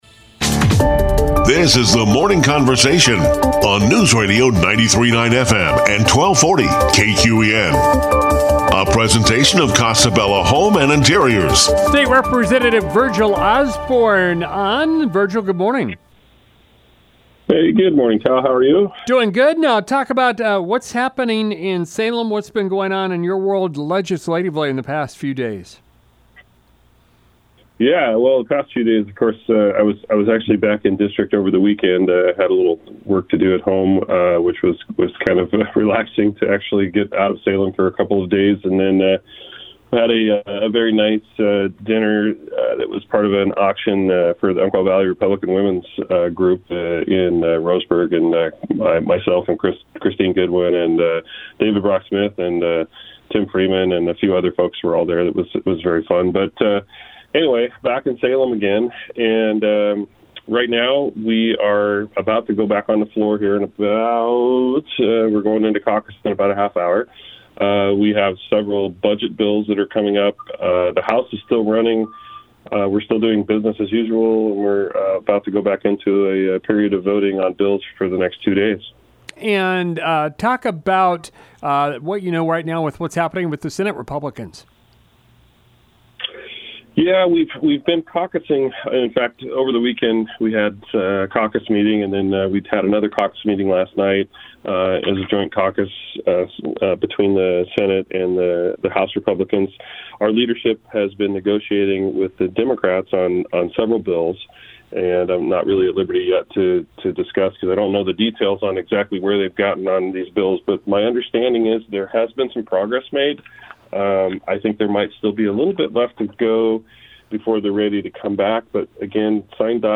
State Representative Virgle Osborne is live from Salem with a legislative update.